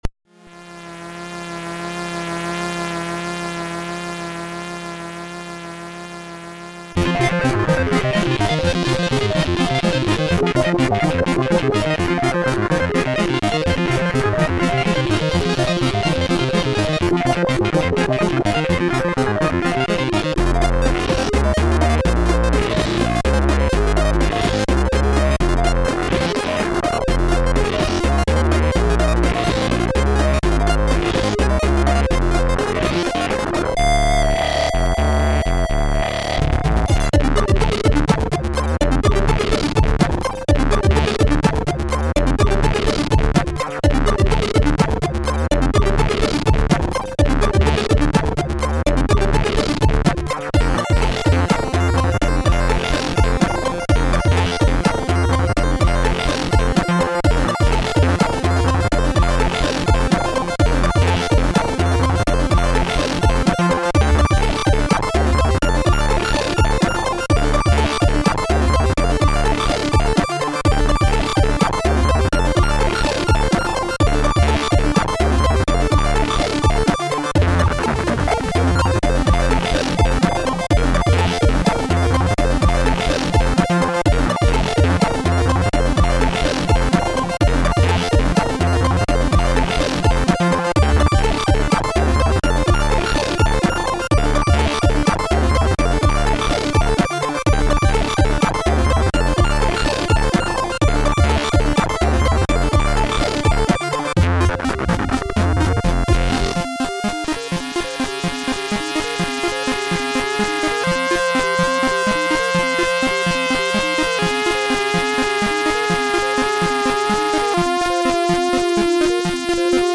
Really powerful piece of music that is just under 2 minutes.